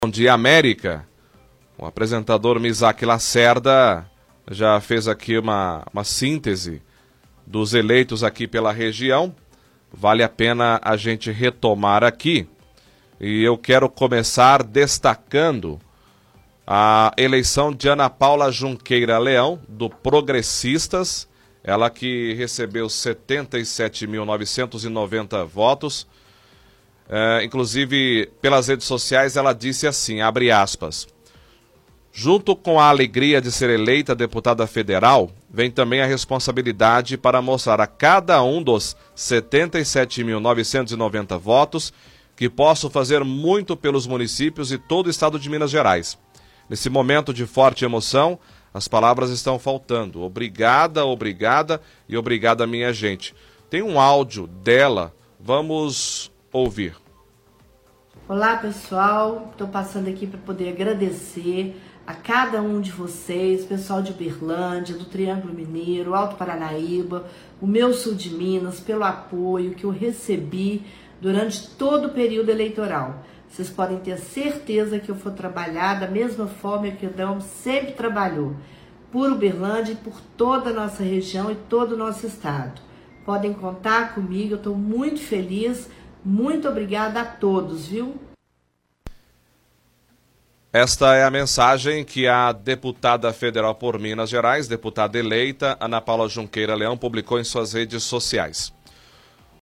Apresentador destaca a eleição de Ana Paula Junqueira Leão, exibe áudio publicado pela candidata eleita em suas redes sociais.